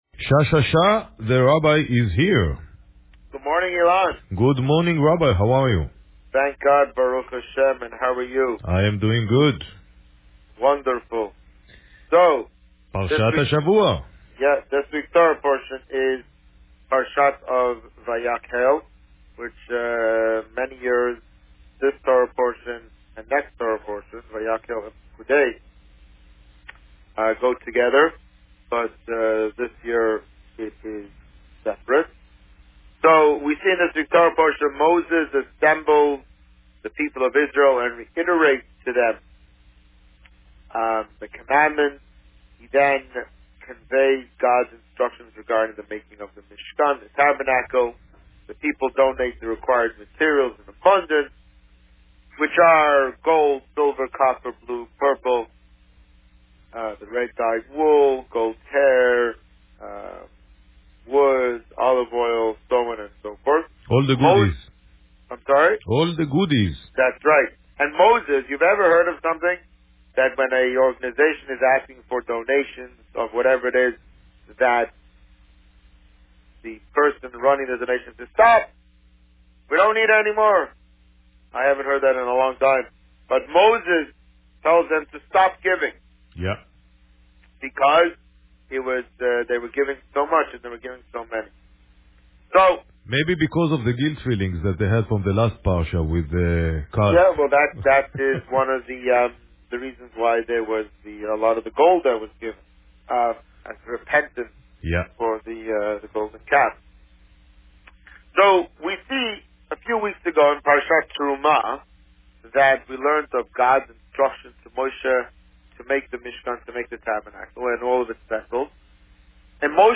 On February 28, 2019, the Rabbi spoke about Parsha Vayakhel and the upcoming Purim festivities. Listen to the interview here.